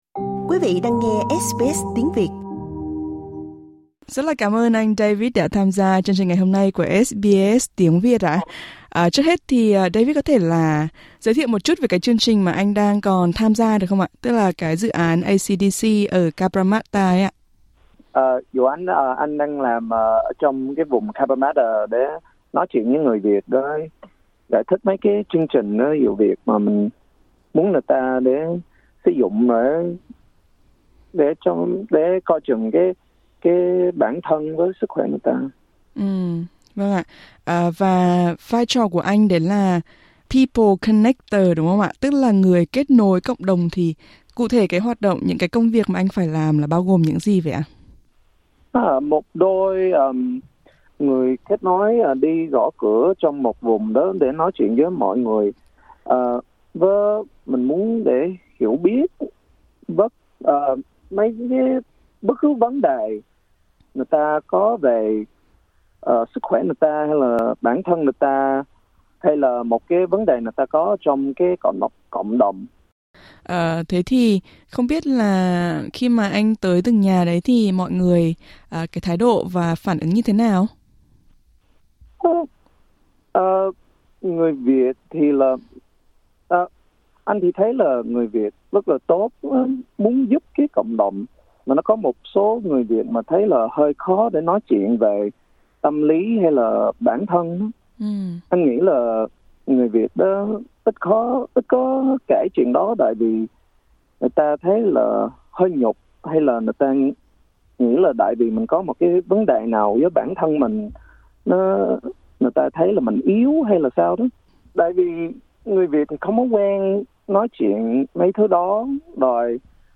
Xin mời quý vị nghe toàn bộ nội dung cuộc trò chuyện.